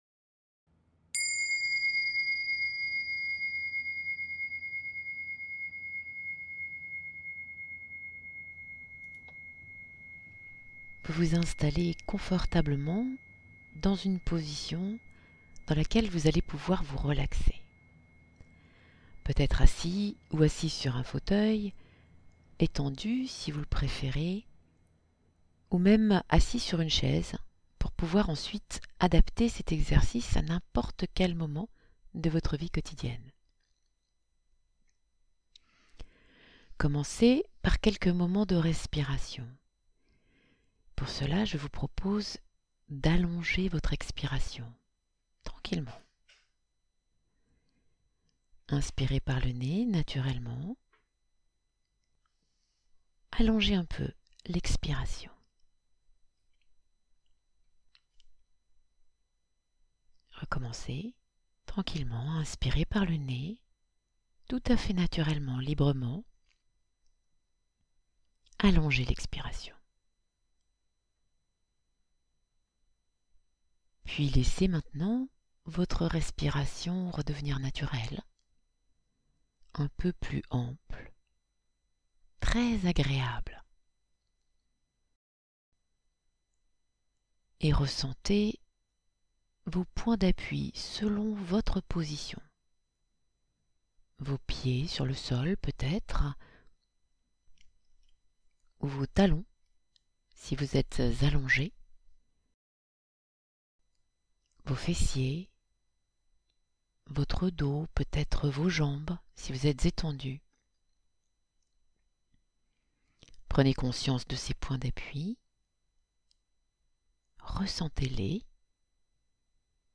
Genre : Speech.